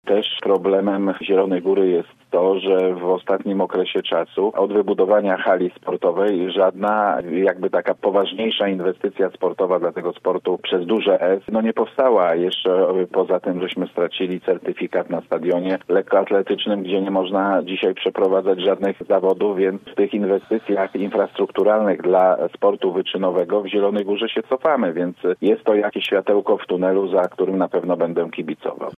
Jeżeli inwestycja dojdzie do skutku, będę się bardzo cieszył – mówi poseł Bogusław Wontor z Nowej Lewicy o planach budowy stadionu piłkarskiego w Zielonej Górze.